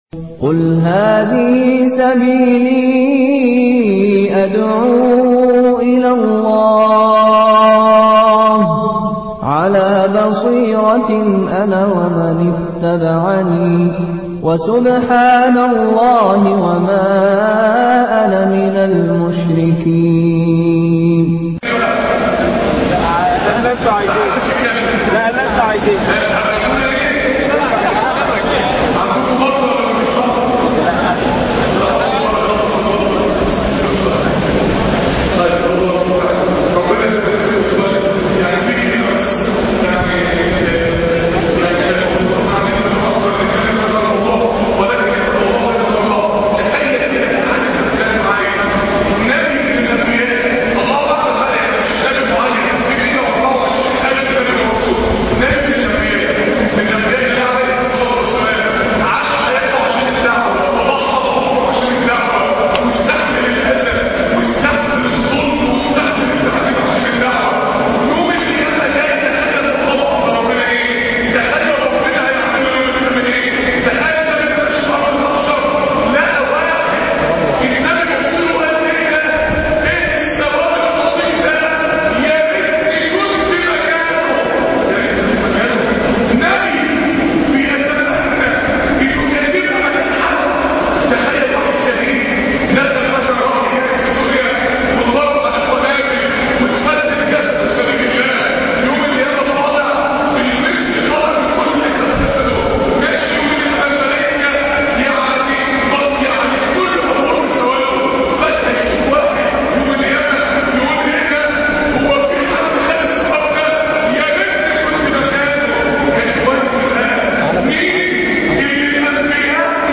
درس ما بعد الجمعة(the real love الحب الحقيقي)